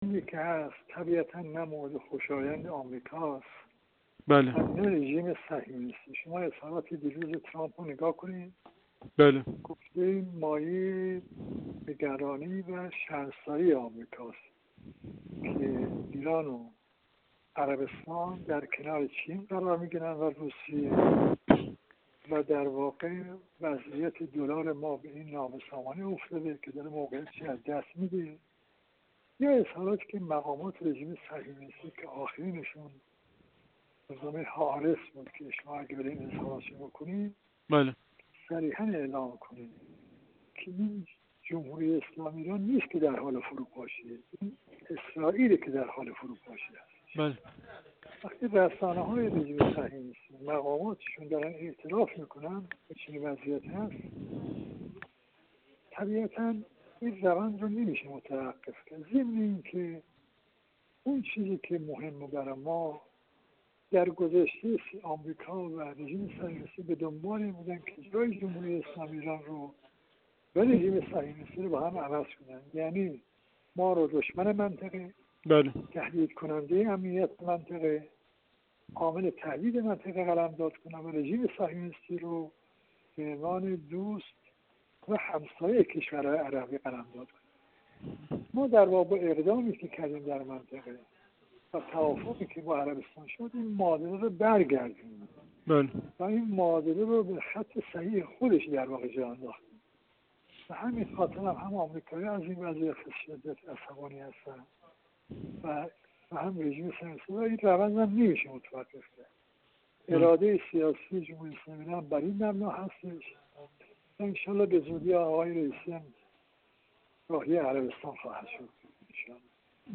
سیدرضا میرابیان، سفیر اسبق جمهوری اسلامی ایران در کویت
سیدرضا میرابیان، سفیر اسبق جمهوری اسلامی ایران در کویت و کارشناس ارشد مسائل غرب آسیا، در گفت‌وگو با ایکنا، درباره تحولات شتابان و دومینووار در منطقه پیرامون احیا و بهبود روابط ایران با کشورهای عربی و افول آشکار رژیم صهیونیستی گفت: روند ایجاد شده در منطقه به هیچ‌وجه خوشایند آمریکایی‌ها و صهیونیست‌ها نیست.